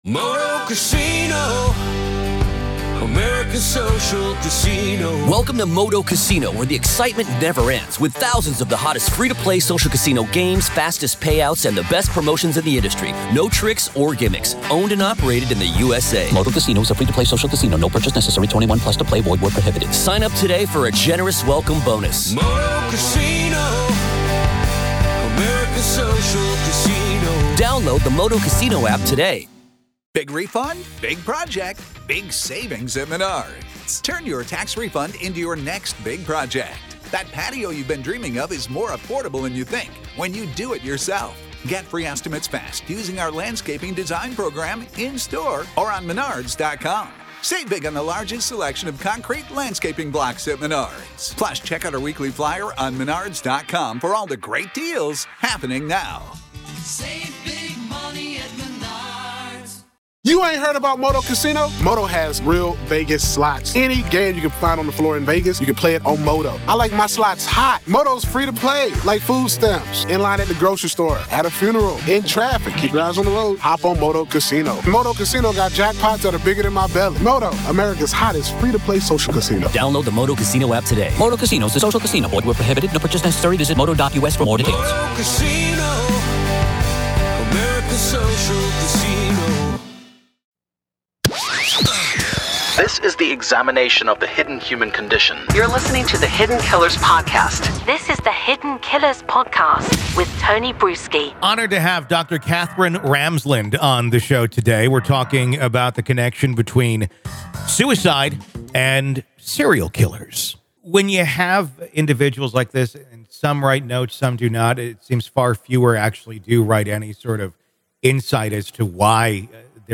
In their discussion, the duo explores the complex psychology of serial killers, particularly focusing on their propensity for suicide and the cryptic messages they leave behind.